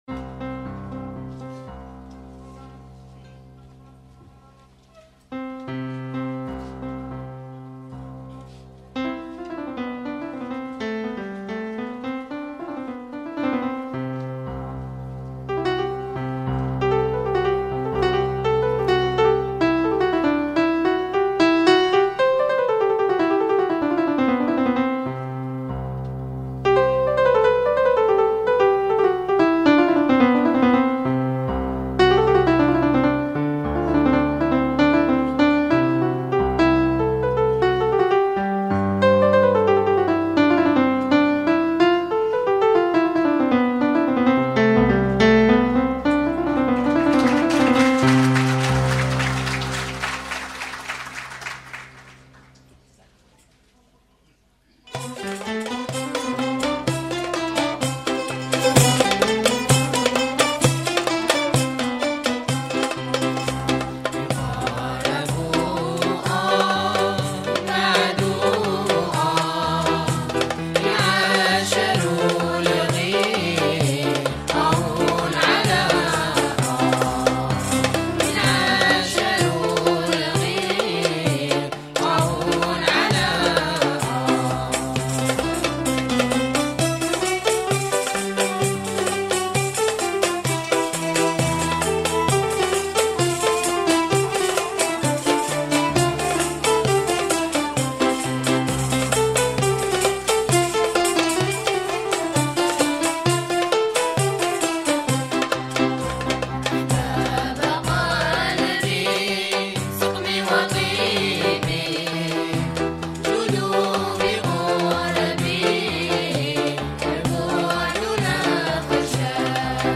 -2- طرب غرناطي
Garnati_ENFANTS_2.mp3